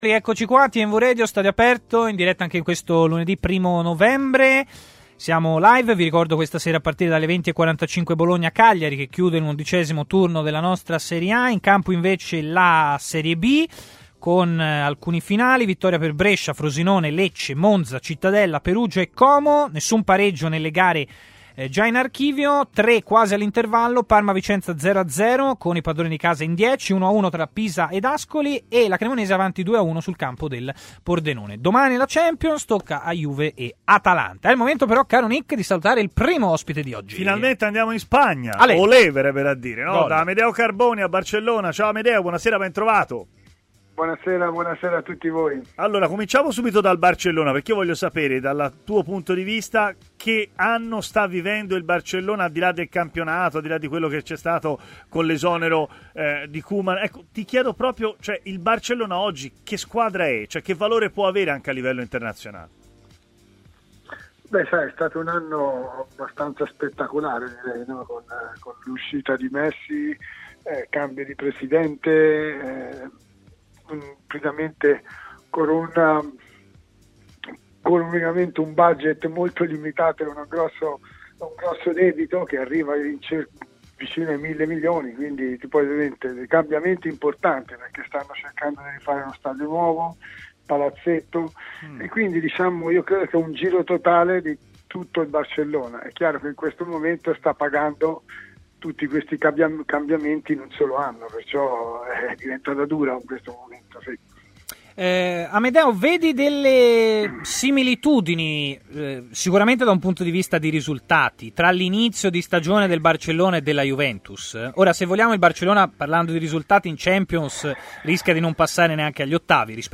L'ex difensore Amedeo Carboni ha parlato in diretta sulle frequenze web di TMW Radio, nel corso della trasmissione Stadio Aperto